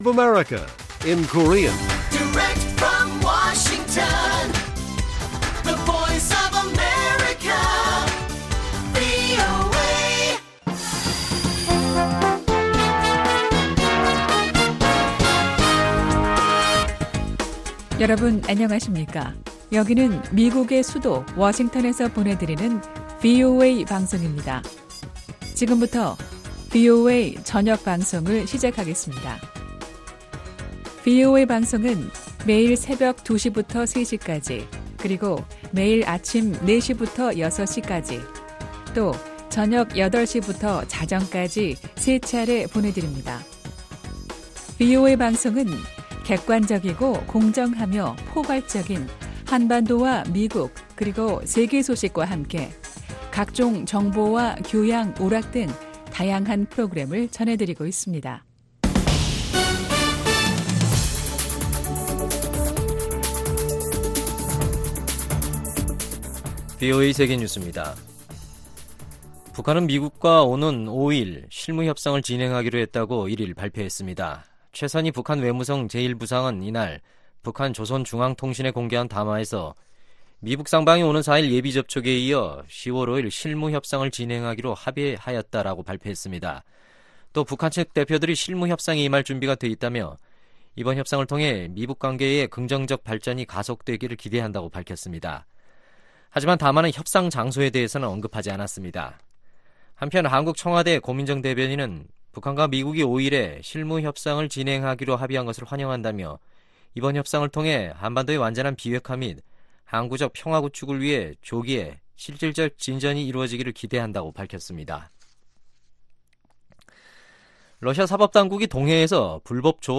VOA 한국어 간판 뉴스 프로그램 '뉴스 투데이', 2019년 9월 30일 1부 방송입니다. 최선희 북한 외무성 제1부상은 오는 10월 5일 미-북 실무협상을 하기로 합의했다고 밝혔습니다. 미국은 한반도에 전술 핵무기를 다시 배치할 계획이 없다고 존 루드 미 국방부 정책차관이 밝혔습니다.